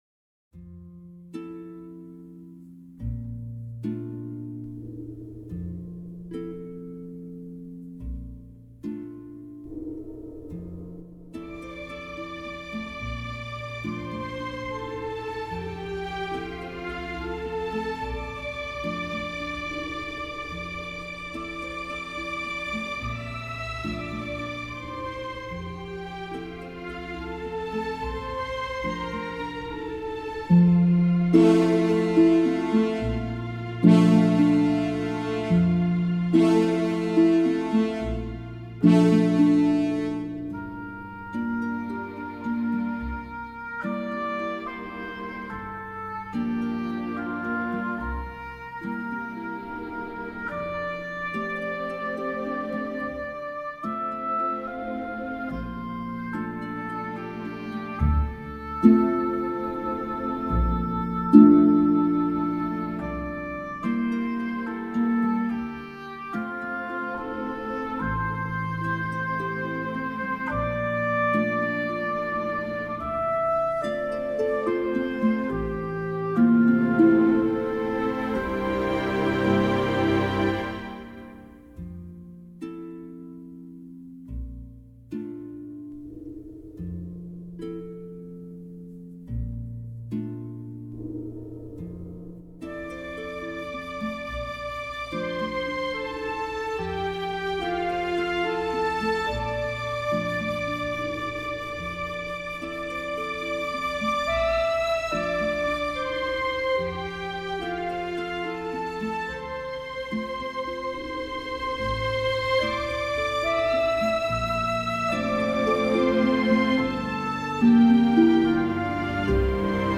Van de stukken voor orkest is er met behulp van StaffPad een synthetische "weergave" worden gemaakt.
Op.72 No.8 Gymnopédie Symfonieorkest augustus 2025 Fluit, hobo, hoorn, harp, strijkers en cymbaal